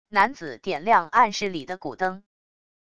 男子点亮暗室里的骨灯wav音频